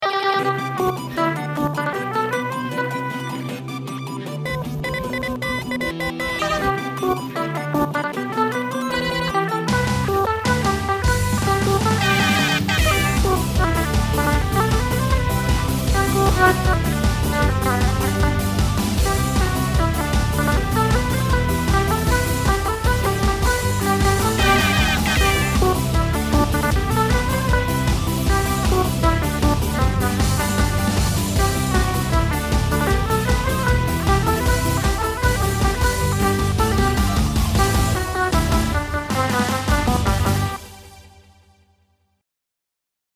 音MAD